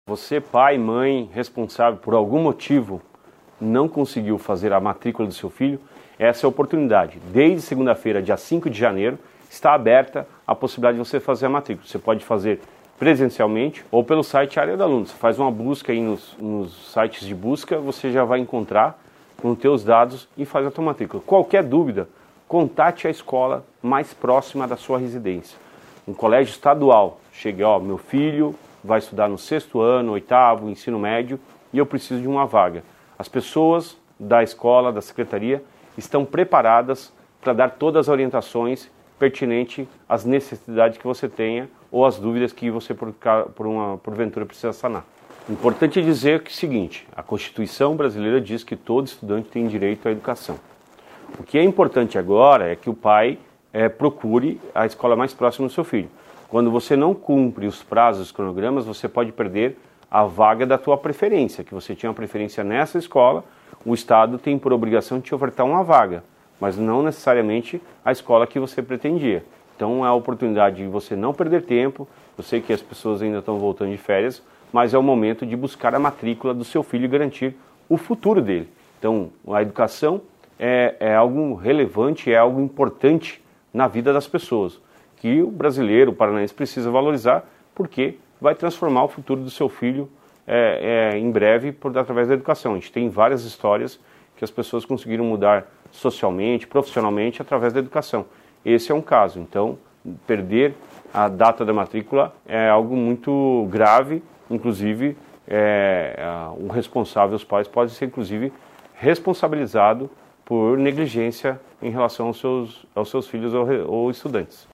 Sonora do secretário Estadual da Educação, Roni Miranda, sobre a abertura de matrículas na rede estadual para quem perdeu o prazo regular